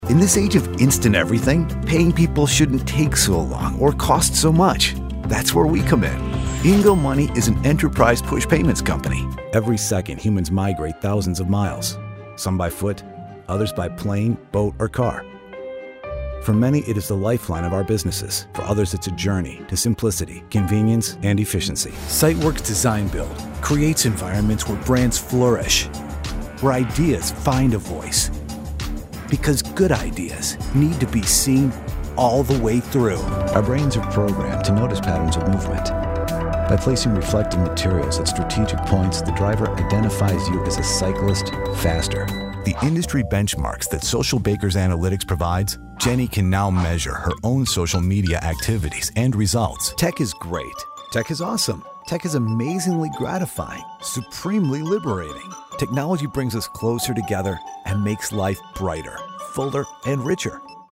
Male
Adult (30-50), Older Sound (50+)
Television Spots
Produced Vo Demo